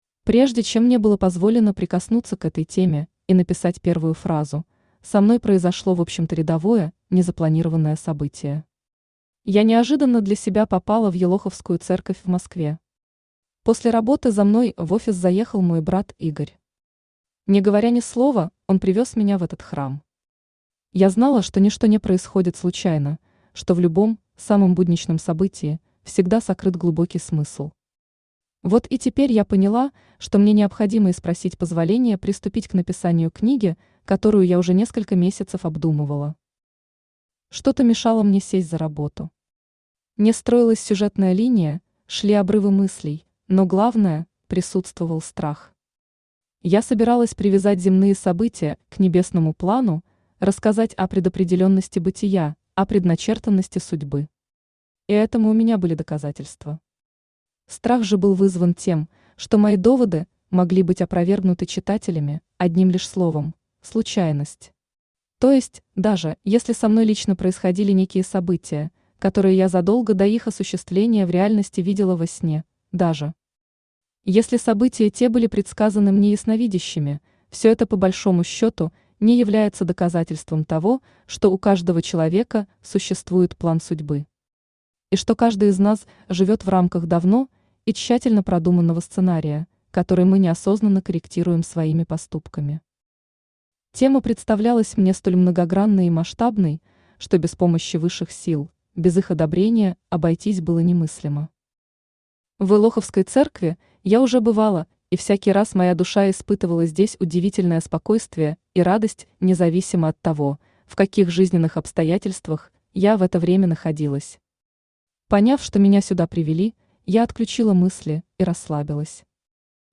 Аудиокнига Между ангелом и бесом, между небом и землей | Библиотека аудиокниг
Aудиокнига Между ангелом и бесом, между небом и землей Автор Елена Феникс Читает аудиокнигу Авточтец ЛитРес.